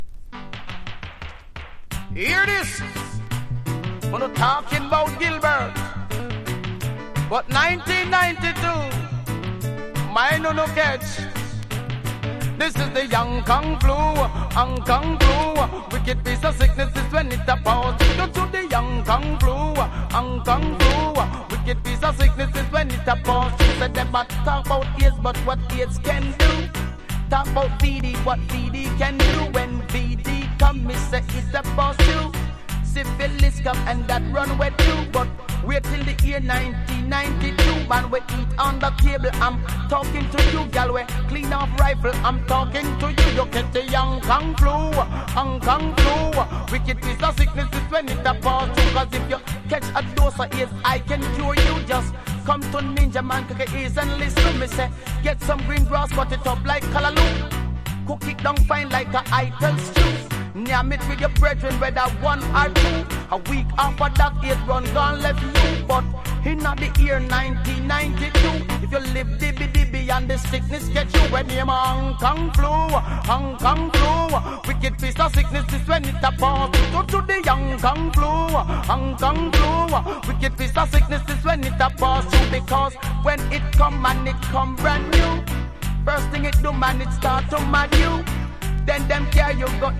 • REGGAE-SKA
DANCE HALL